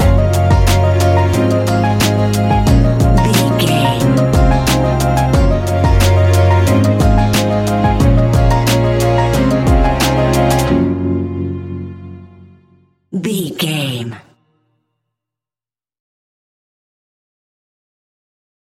Ionian/Major
F♯
Lounge
sparse
new age
chilled electronica
ambient
atmospheric
morphing
instrumentals